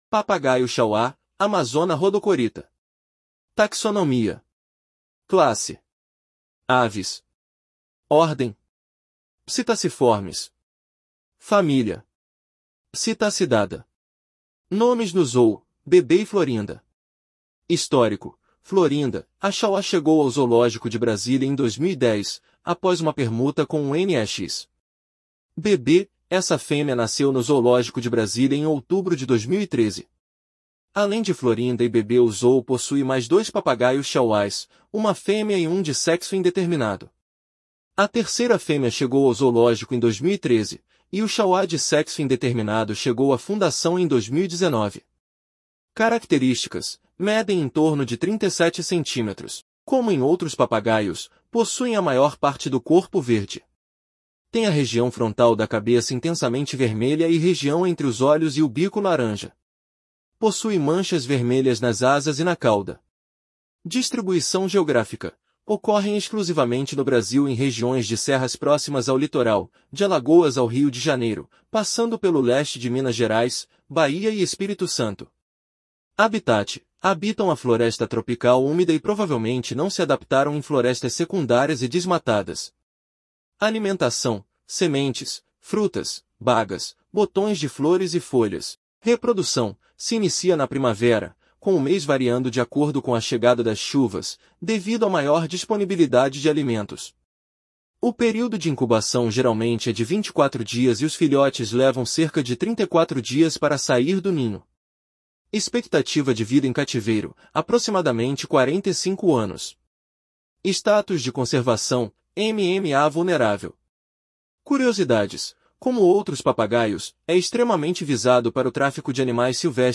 Papagaio-chauá (Amazona rhodocorytha)